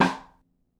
DrRim7.wav